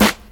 • Original Snare Drum Sample F# Key 01.wav
Royality free steel snare drum tuned to the F# note. Loudest frequency: 2288Hz
original-snare-drum-sample-f-sharp-key-01-31b.wav